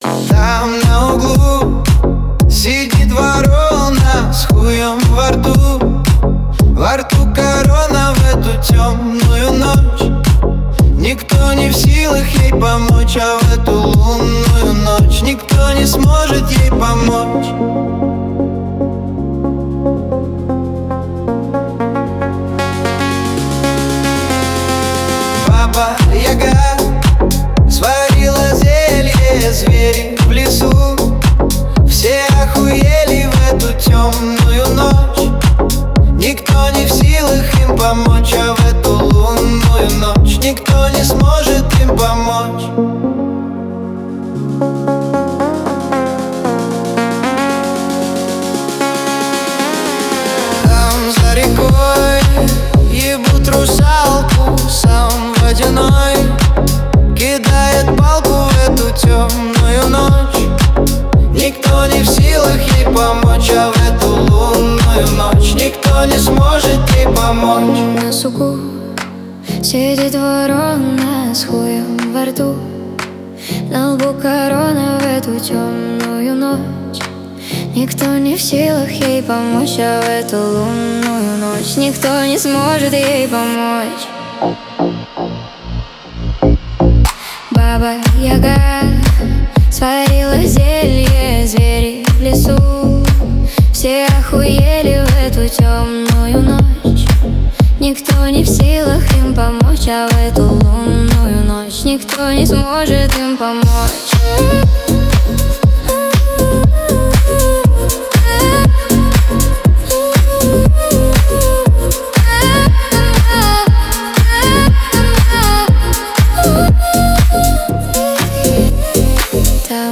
Танцевальная